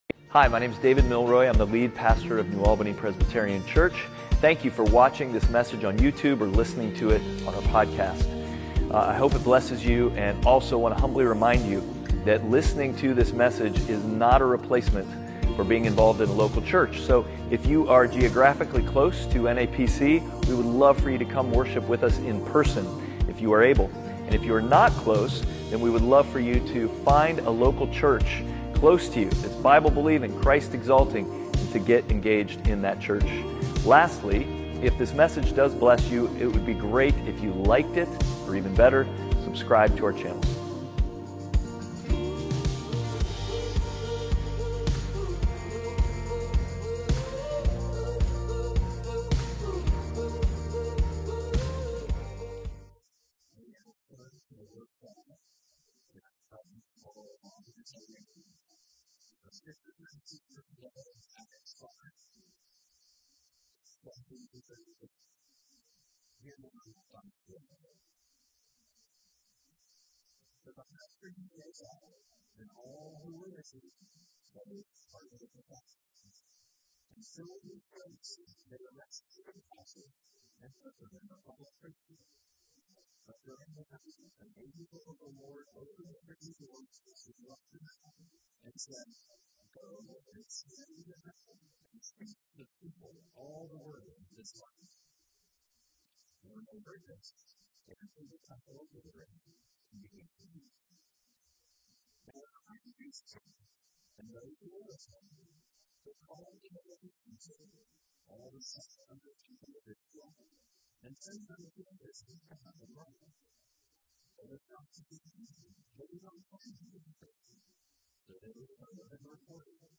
Outward Passage: Acts 5:17-32 Service Type: Sunday Worship « Outward